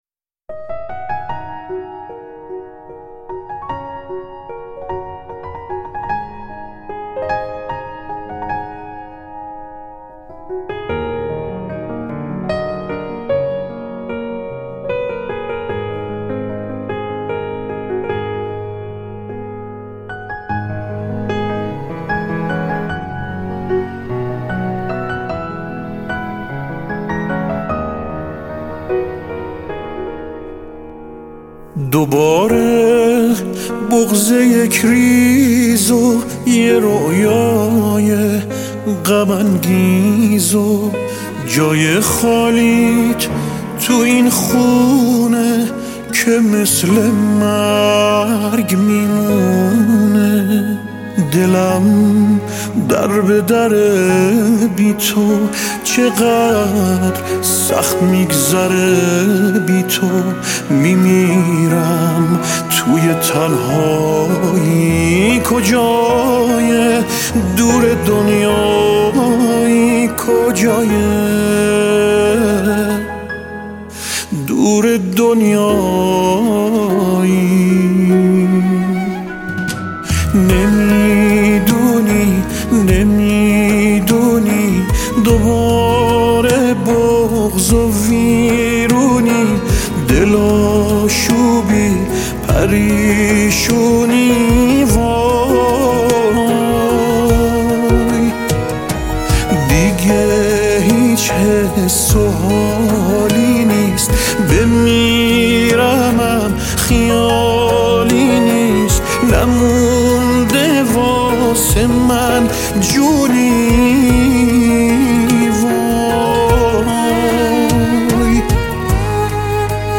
پاپ شاد